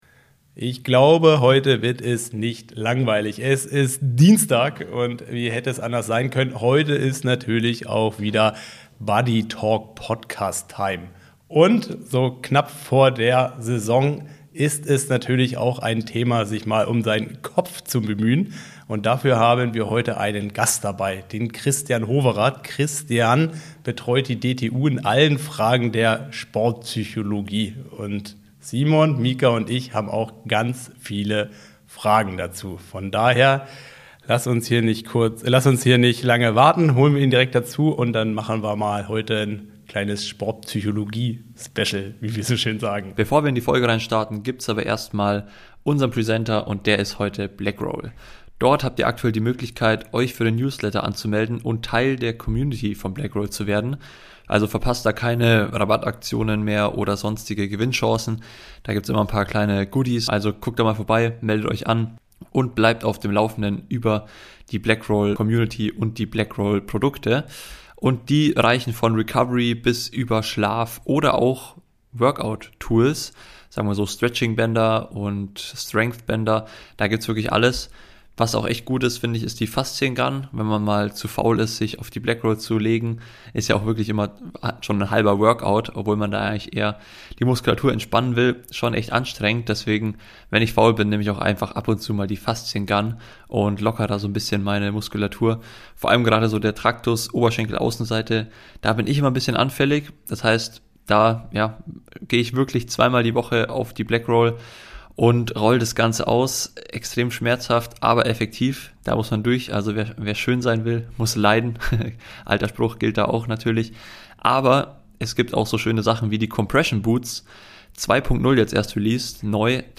Im lockeren Talk diskutieren wir, wie mentale Gesundheit und gezieltes Mindset-Training echte Leistungsreserven freisetzen können – nicht nur im Rennen, sondern auch im Alltag. Neben persönlichen Erfahrungen und Strategien gegen Leistungsdruck und Verletzungen, geht es um den Umgang mit Social Media, Selbstzweifel und den oft unterschätzten Wert von Routinen.